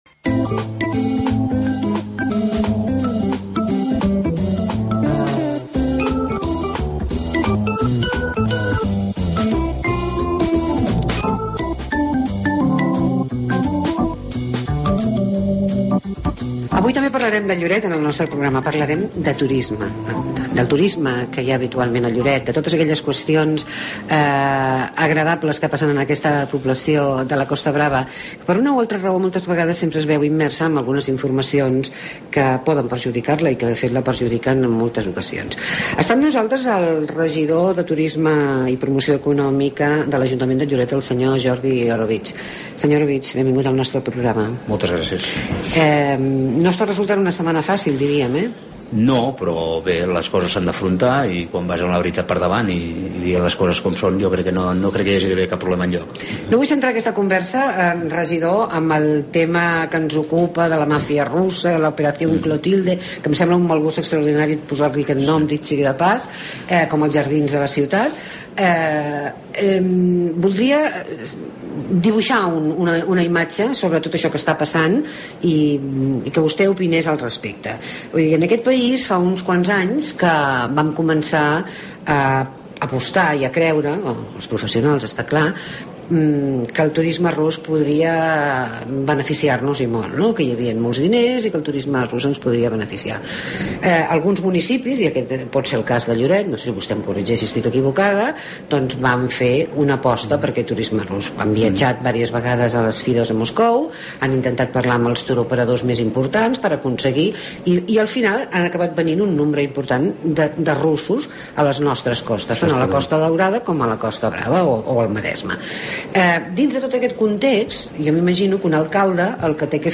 AUDIO: Entrevista a Jordi Orobitg, regidor de Turisme i promoció econòmica de Lloret